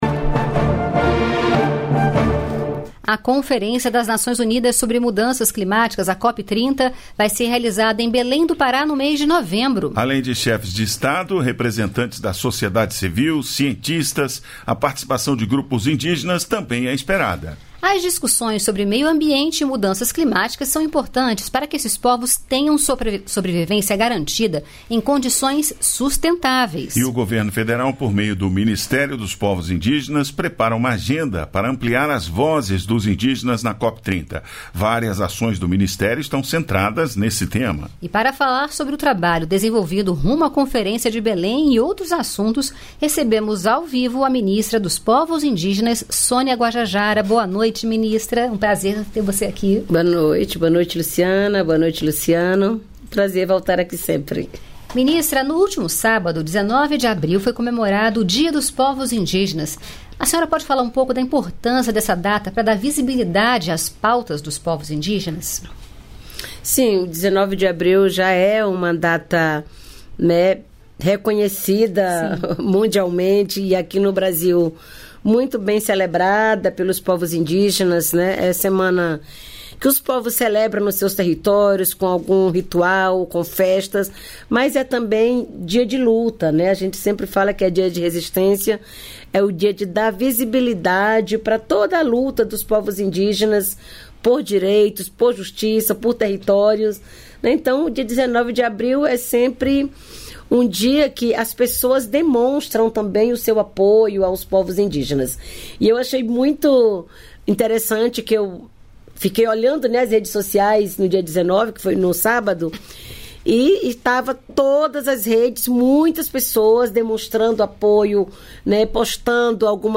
Em entrevista, a ministra Sonia Guajajara falou sobre os preparativos para a Conferência das Nações Unidas sobre as Mudanças Climáticas (COP 30), que vai ser realizada em Belém do Pará, no mês de novembro.